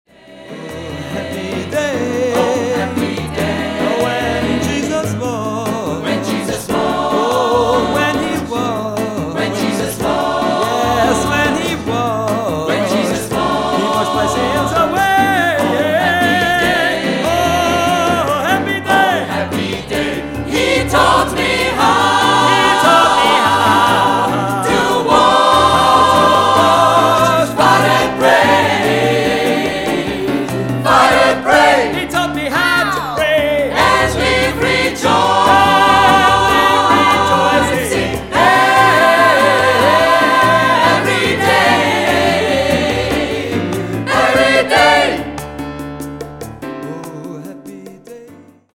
Vokalensemble MIX-DUR - Hörbeispiele